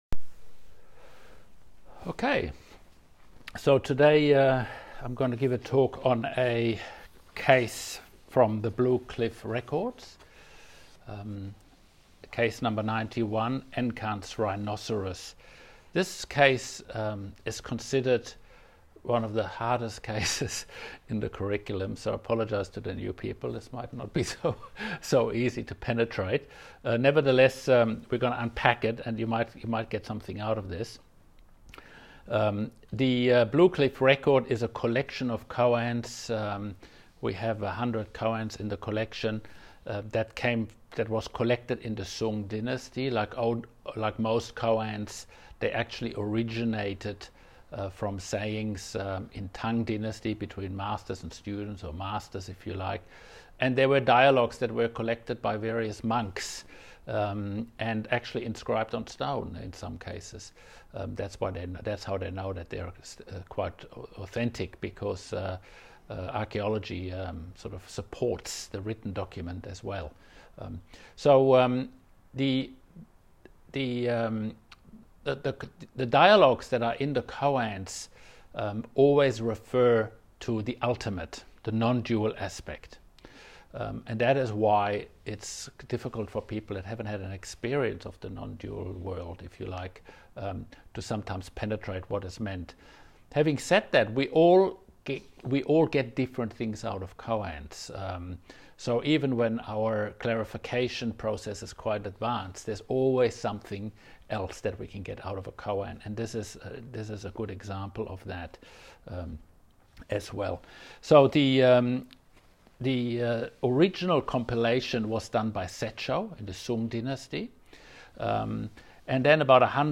Instruction